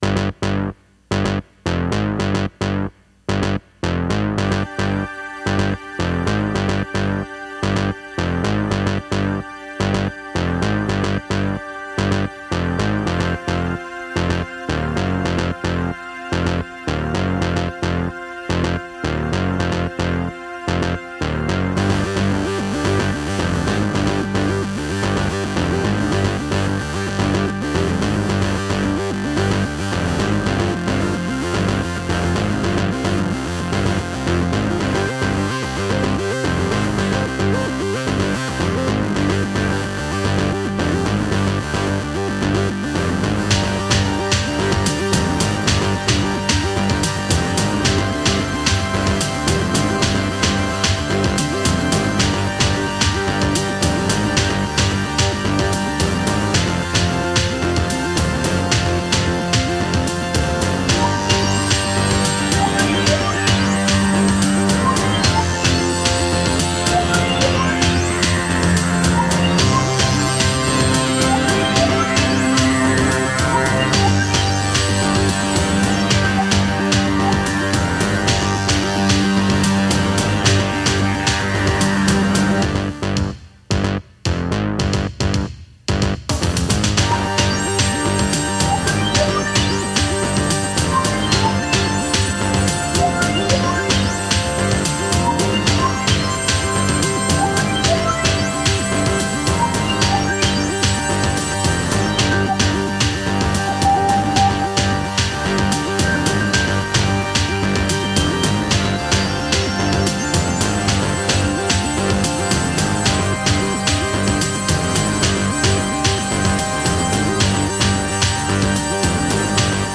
instrumental
Recorded AAD - Analogue Analogue Digital